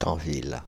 Tanville (French pronunciation: [tɑ̃vil]
Fr-Tanville.ogg.mp3